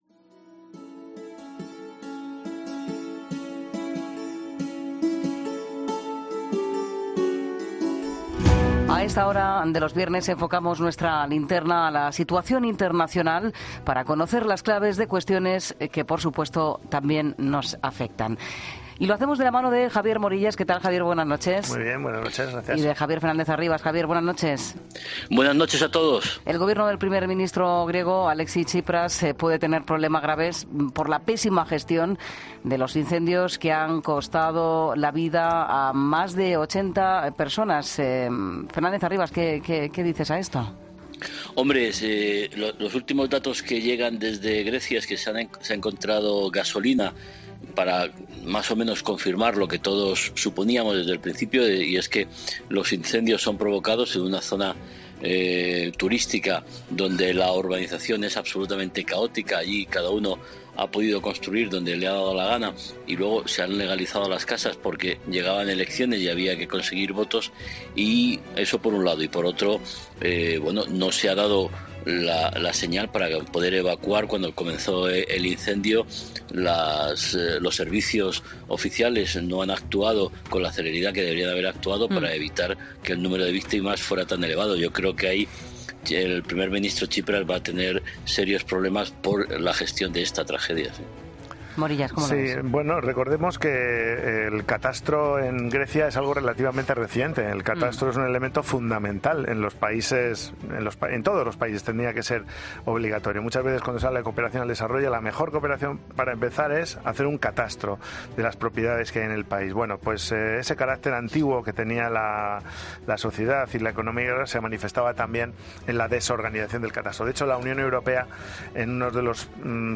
Tertulia Internacional en 'La Linterna', viernes 27 de julio de 2018